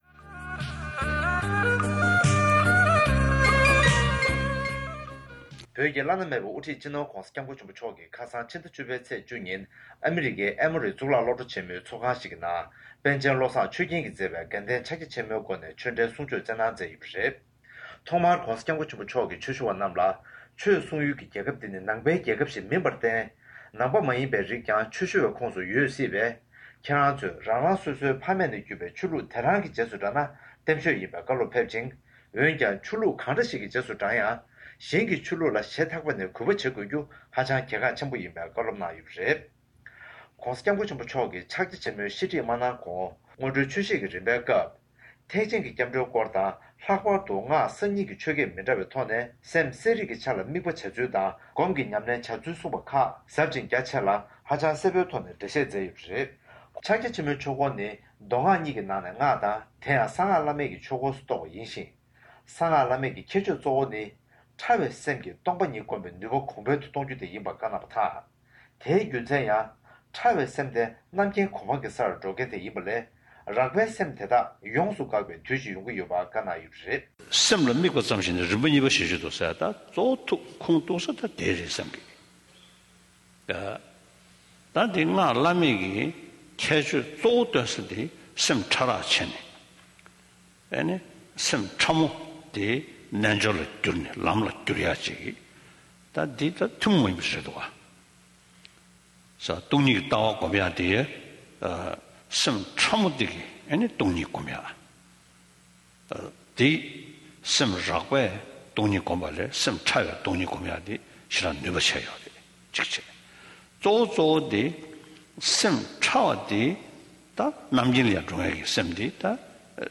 ས་གནས་ནས་བཏང་བའི་གནས་ཚུལ་ལ་གསན་རོགས༎